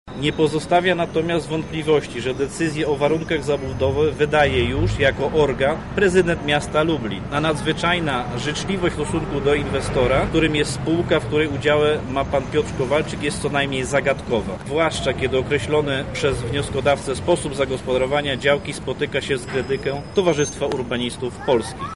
• mówi Piotr Breś radny z klubu Prawa i Sprawiedliwości.